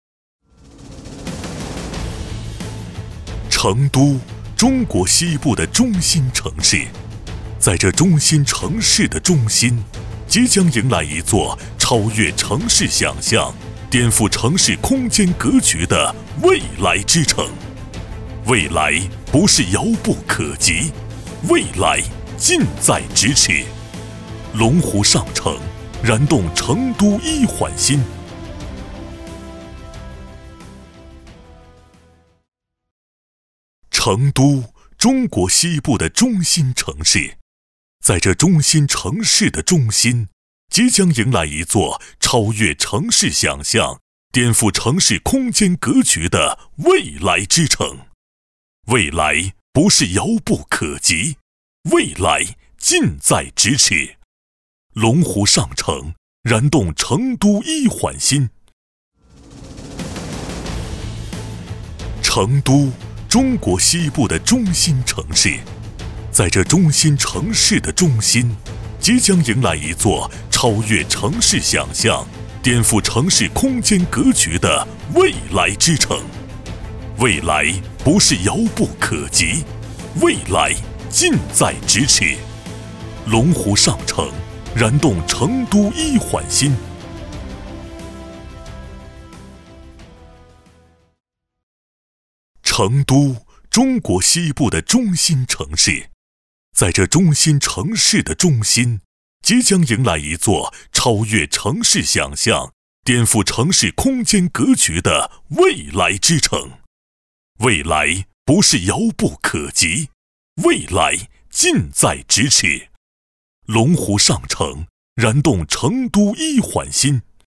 国语青年大气浑厚磁性 、沉稳 、男专题片 、宣传片 、60元/分钟男S310 国语 男声 专题片 市中区人民政府专题片【大气政府】 大气浑厚磁性|沉稳 - 样音试听_配音价格_找配音 - voice666配音网
国语青年大气浑厚磁性 、沉稳 、男专题片 、宣传片 、60元/分钟男S310 国语 男声 专题片 市中区人民政府专题片【大气政府】 大气浑厚磁性|沉稳